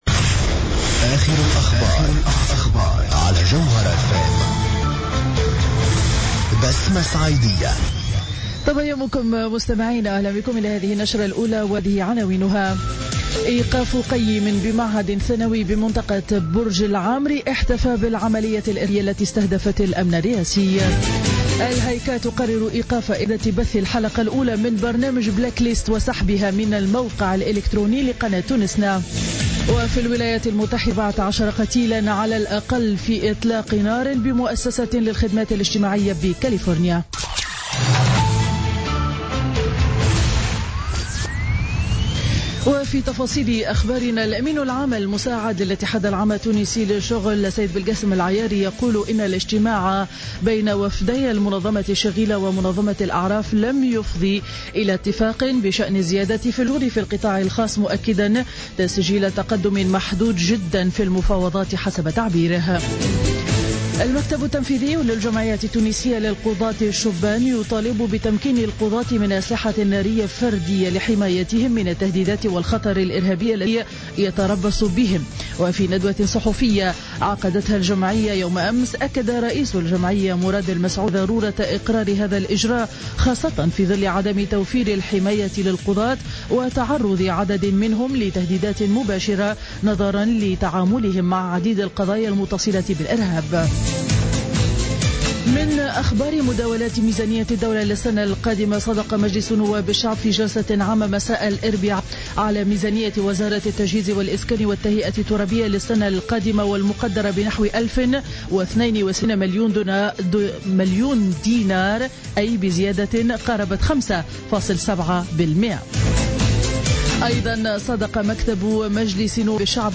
نشرة الأخبار السابعة صباحا ليوم الخميس 3 ديسمبر 2015